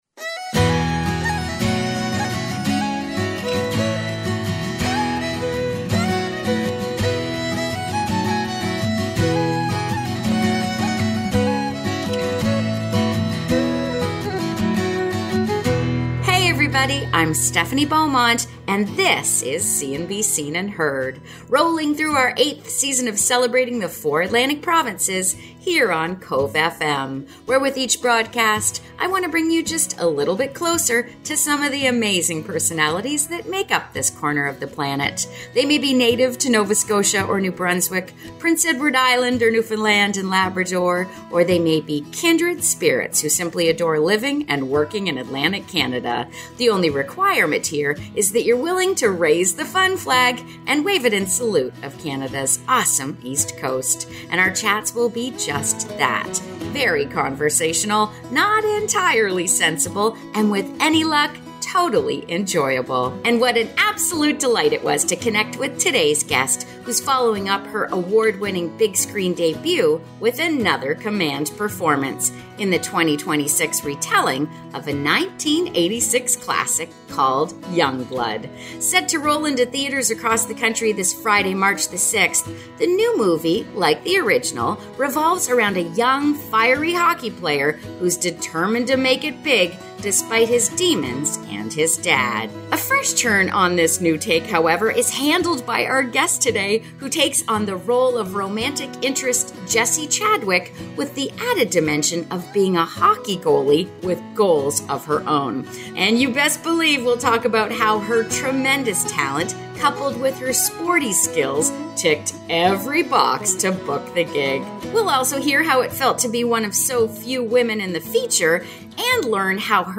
2026 Our chats will be just that, very conversational, not entirely sensible and with any luck totally enjoyable. And what an absolute delight it was to connect with today’s guest, who’s following up her award-winning big screen debut with another command performance in the 2026 retelling of the 1986 classic […]